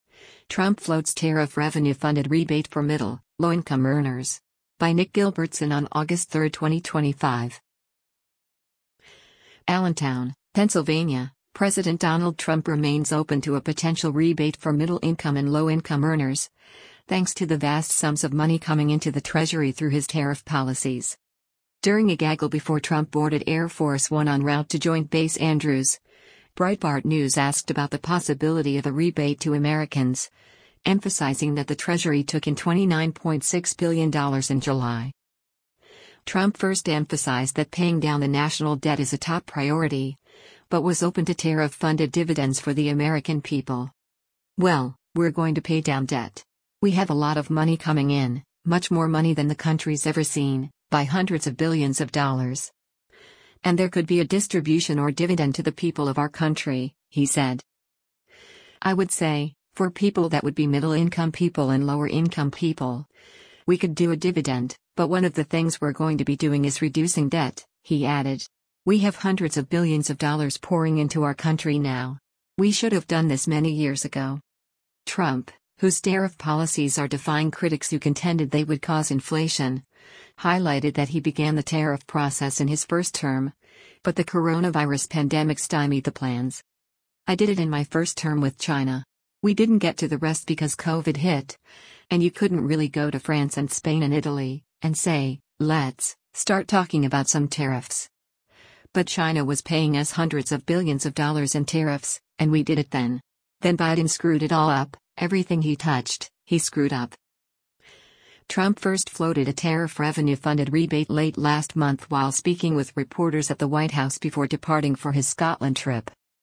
During a gaggle before Trump boarded Air Force One en route to Joint Base Andrews, Breitbart News asked about the possibility of a rebate to Americans, emphasizing that the Treasury took in $29.6 billion in July.